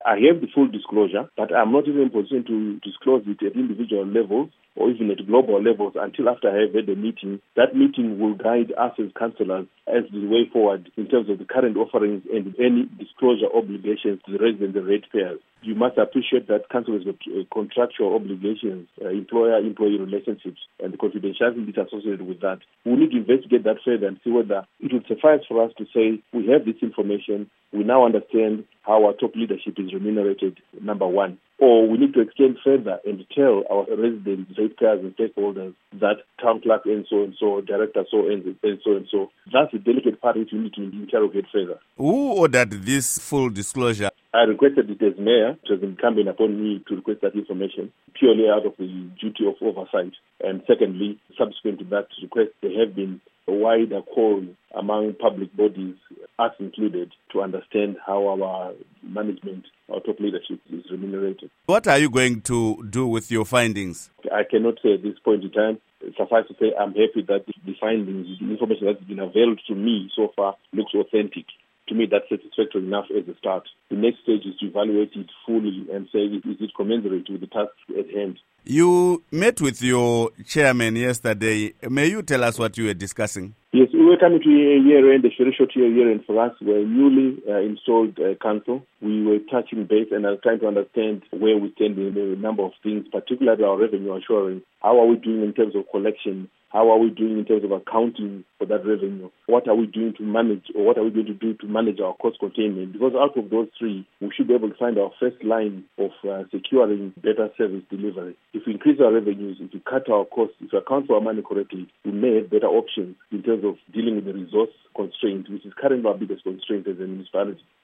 Interview With Bernard Manyenyeni